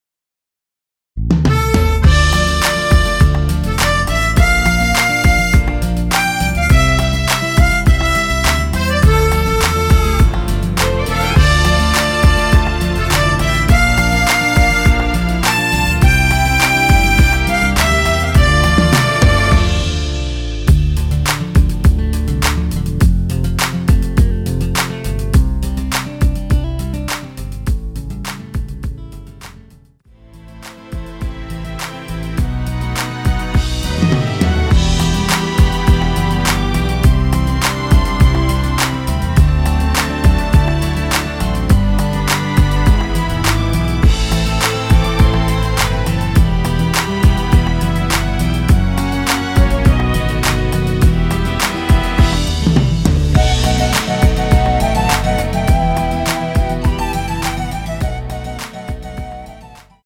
원키에서(-2)내린 MR입니다.
Dm
앞부분30초, 뒷부분30초씩 편집해서 올려 드리고 있습니다.
중간에 음이 끈어지고 다시 나오는 이유는